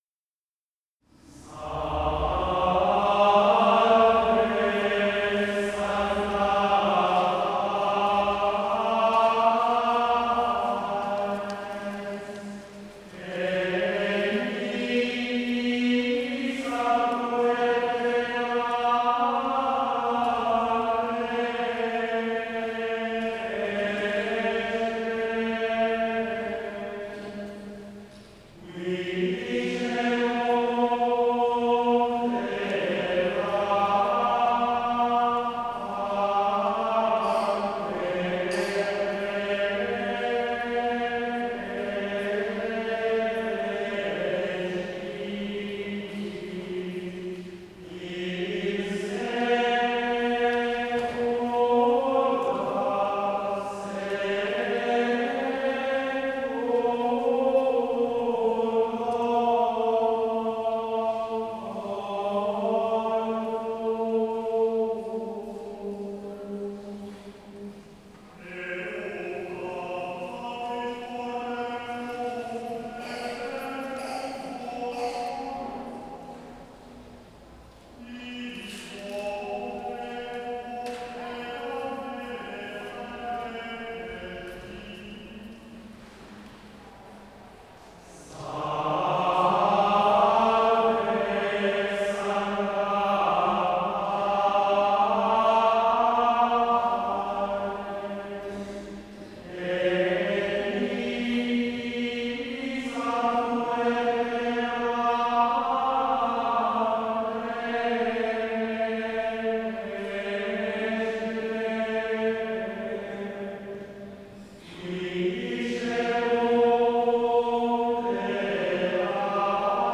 O Canto Gregoriano